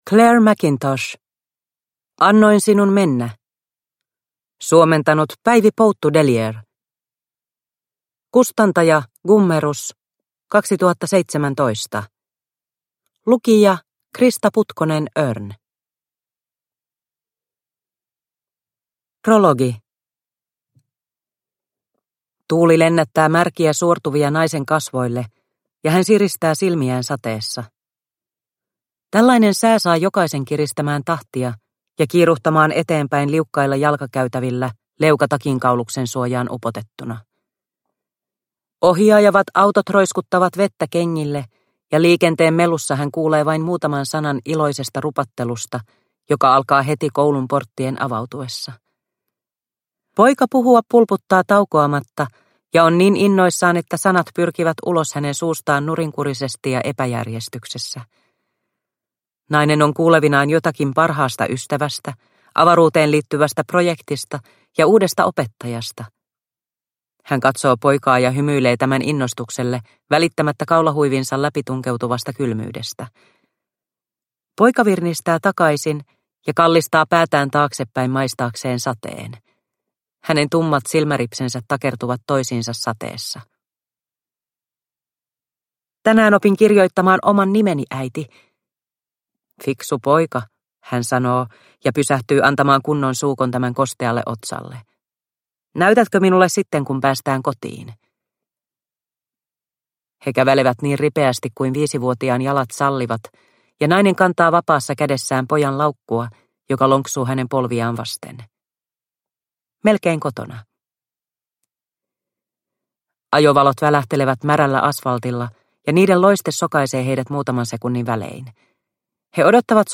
Annoin sinun mennä – Ljudbok – Laddas ner